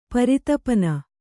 ♪ paritapana